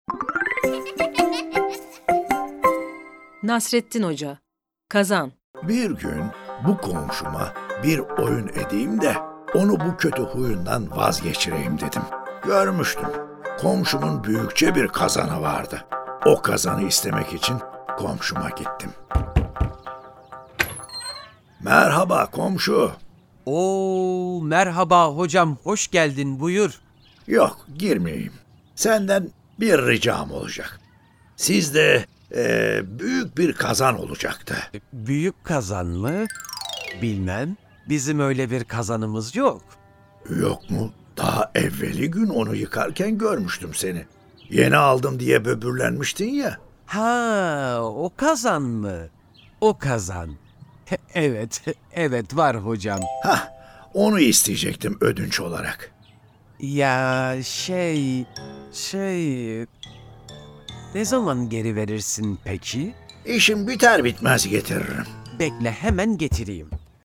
Nasreddin Hoca: Kazan Tiyatrosu